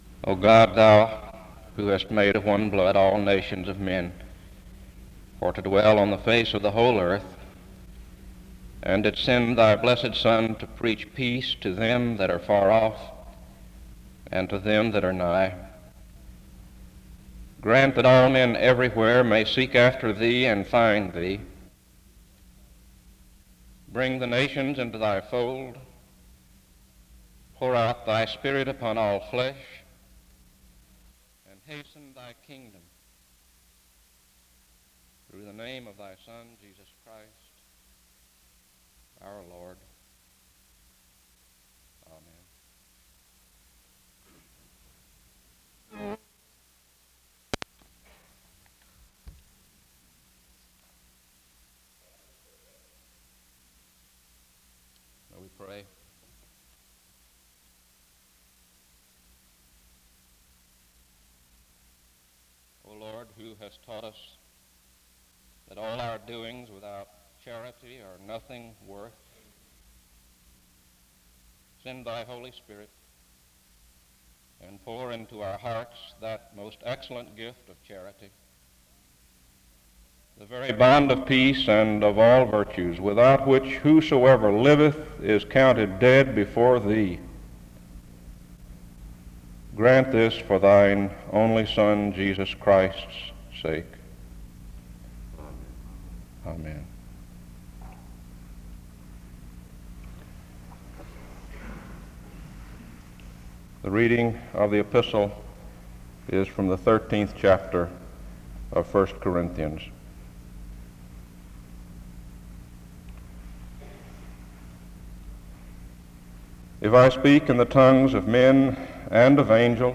The service opens with a word of prayer from 0:00-1:36. 1 Corinthians 13 is read from 1:43-4:36. A prayer is offered from 4:55-7:12. An excerpt from the Gospel of Luke is read from 7:15-9:38. Rev. Kitagawa speaks from 9:57-52:01. Dr. Kitagawa preaches on the different frontiers where the gospel needs to be proclaimed.